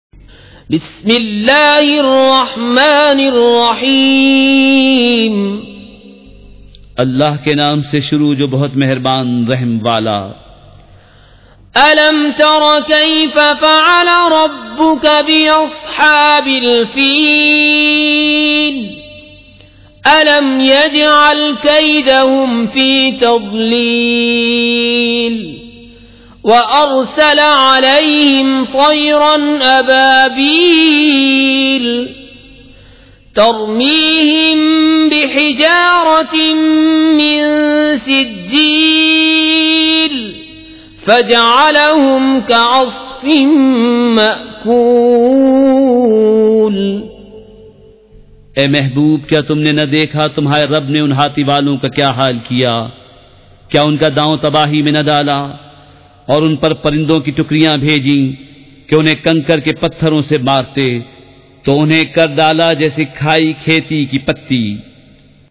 سورۃ الفیل مع ترجمہ کنزالایمان ZiaeTaiba Audio میڈیا کی معلومات نام سورۃ الفیل مع ترجمہ کنزالایمان موضوع تلاوت آواز دیگر زبان عربی کل نتائج 3426 قسم آڈیو ڈاؤن لوڈ MP 3 ڈاؤن لوڈ MP 4 متعلقہ تجویزوآراء
surah-al-fil-with-urdu-translation.mp3